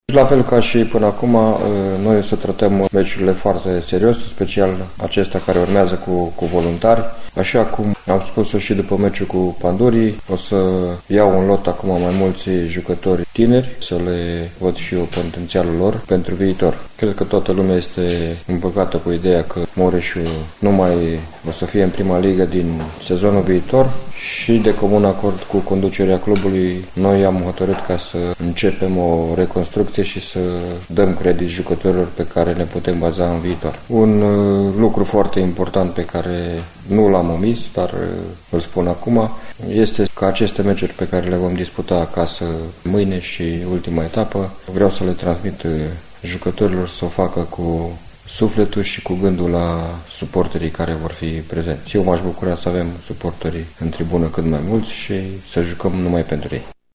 Antrenorul echipei mureșene, Ionel Ganea, a declarat, marți, în cadrul unei conferințe de presă, că încearcă să-și motiveze jucătorii pentru ultimele partide rămase din acest campionat și că va folosi mai mulți tineri, pentru a le vedea potențialul în perspectiva viitorului sezon competițional.